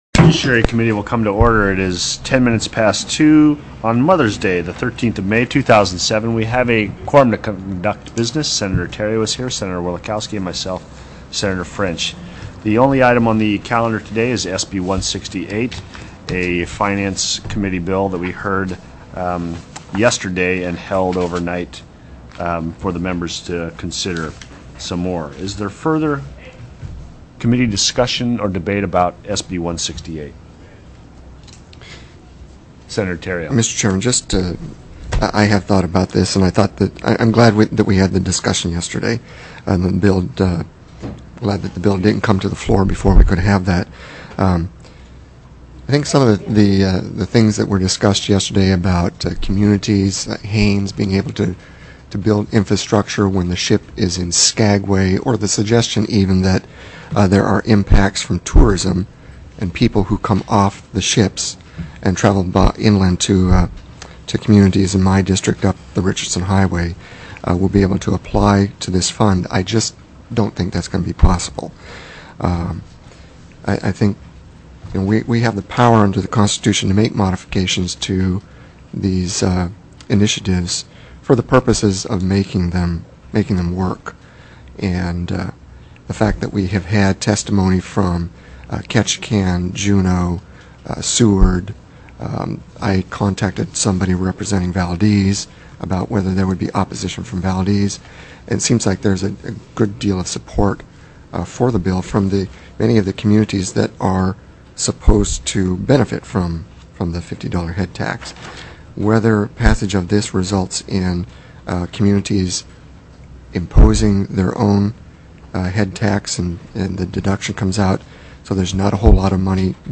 05/13/2007 02:00 PM Senate JUDICIARY
SENATE JUDICIARY STANDING COMMITTEE
Senator Hollis French, Chair